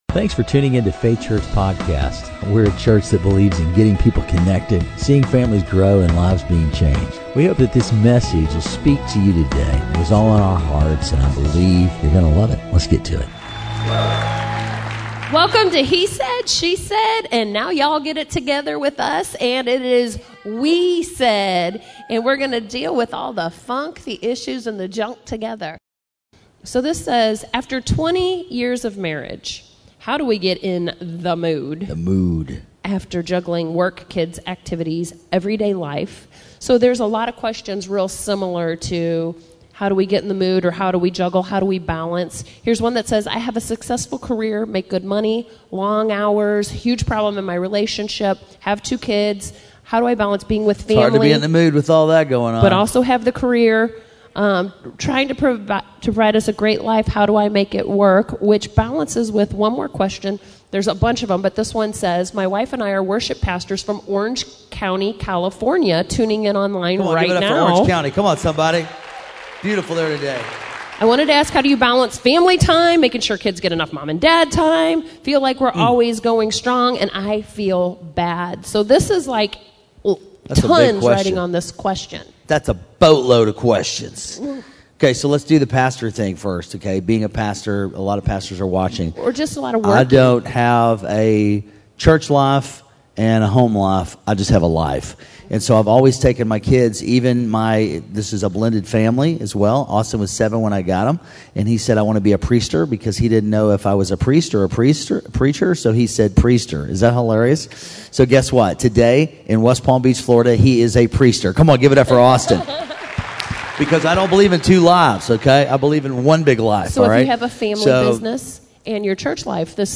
In this conversational message, we get two perspectives on how to make our relationships deeper and last a lifetime.